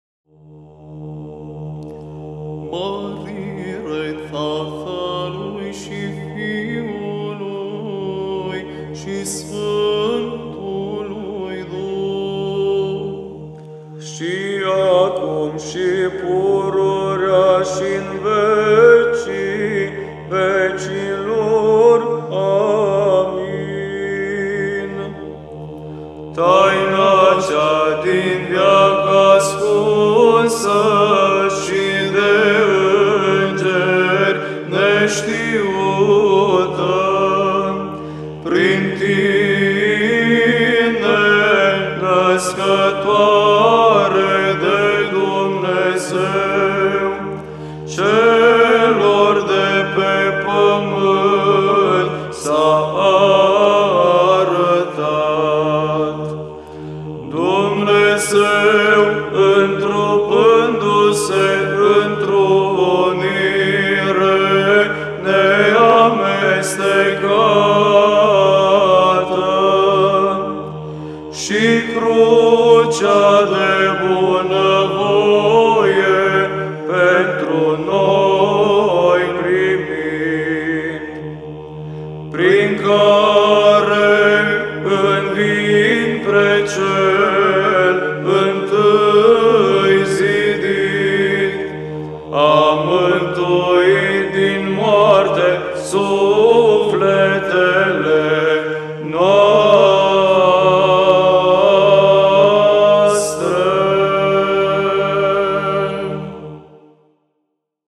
Glasurile bisericești – varianta de tradiție bizantină TRIFON LUGOJAN
GLAS IV
15.-Glas-IV-Marire...-Si-acum...-Troparul-Nascatoarei-de-Dumnezeu.mp3